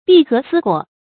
闭合思过 bì gé sī guò
闭合思过发音